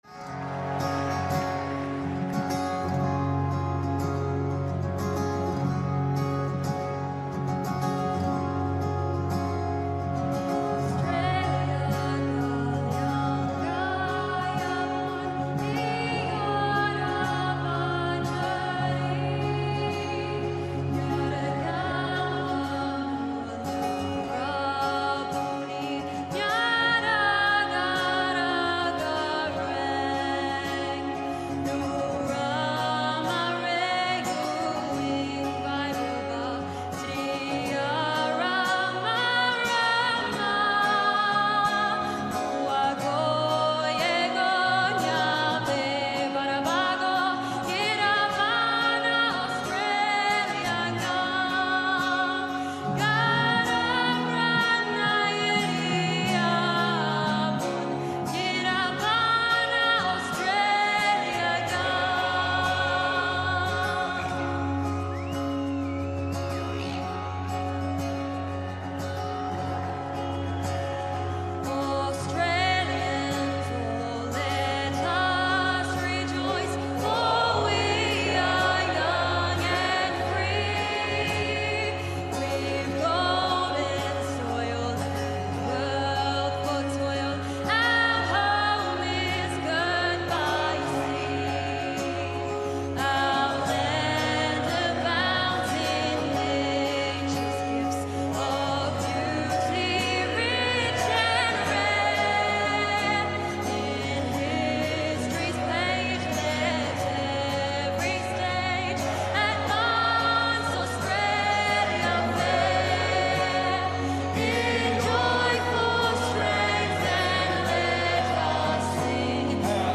Музыка коренных австралийских племен с текстом